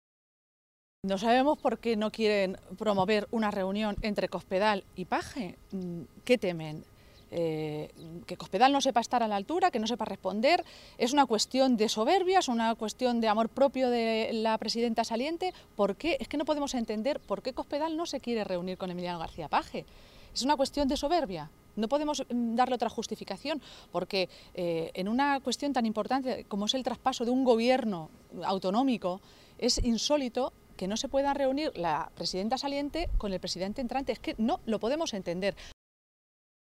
Se pronunciaba Maestre de esta manera esta mañana, en una comparecencia ante los medios de comunicación, en Toledo, durante la reunión de Page con los otros 14 diputados regionales socialistas electos en las elecciones del pasado 24 de Mayo.